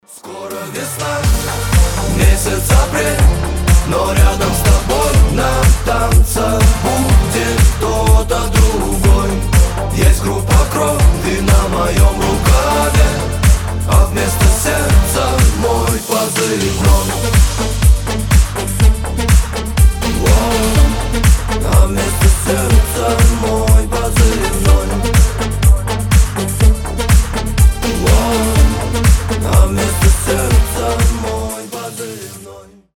• Качество: 320, Stereo
приятный мужской голос